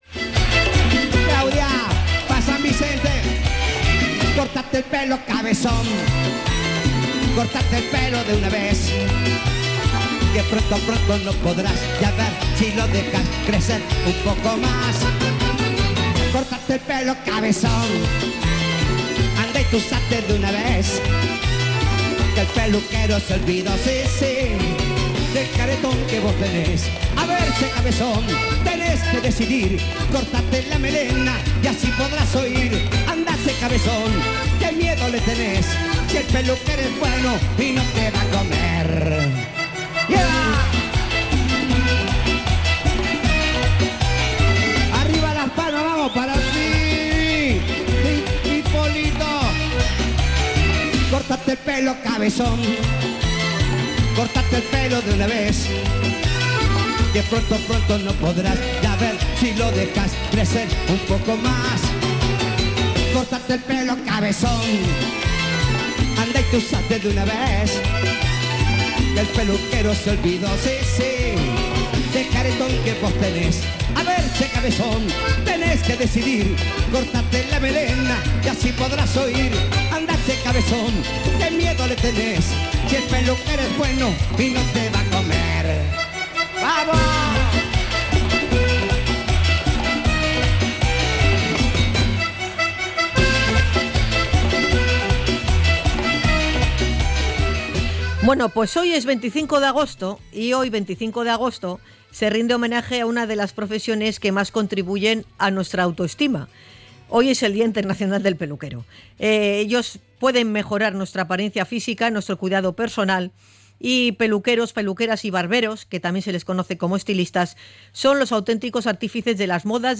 INT.-DIA-INTERNACIONAL-PELUQUERIA-2025.mp3